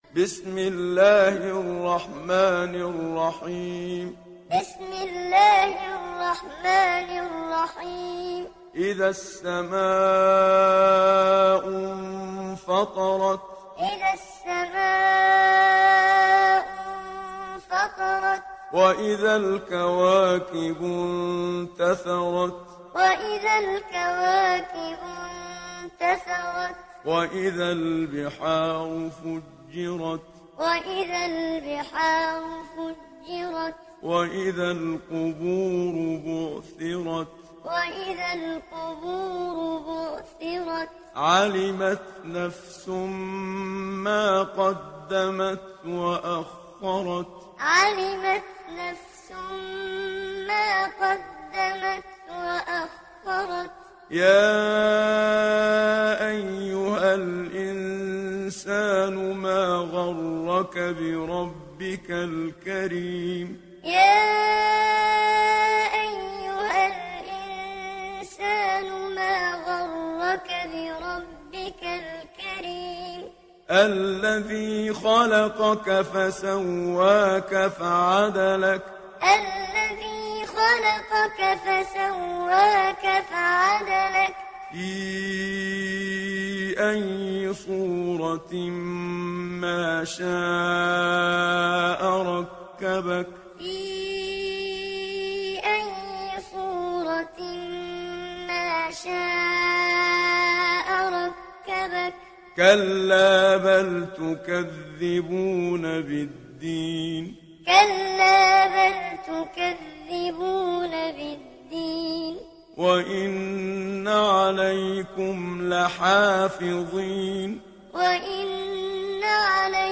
تحميل سورة الانفطار mp3 بصوت محمد صديق المنشاوي معلم برواية حفص عن عاصم, تحميل استماع القرآن الكريم على الجوال mp3 كاملا بروابط مباشرة وسريعة
تحميل سورة الانفطار محمد صديق المنشاوي معلم